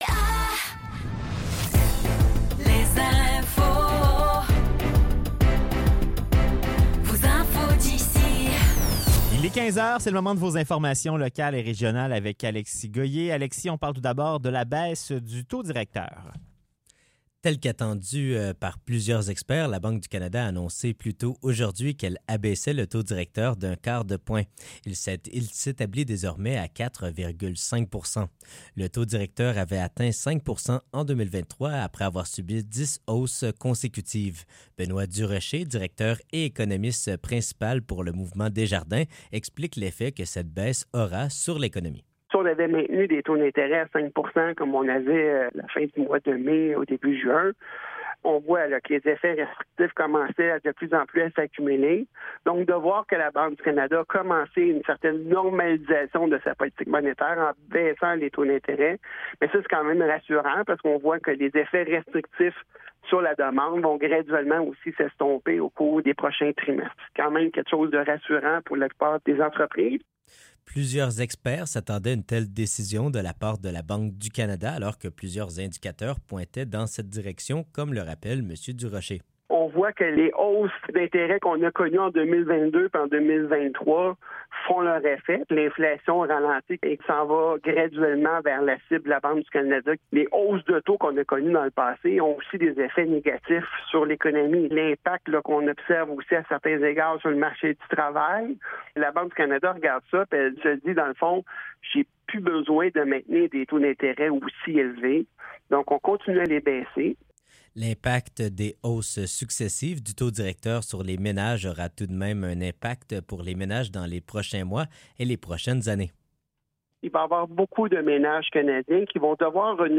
Nouvelles locales - 24 juillet 2024 - 15 h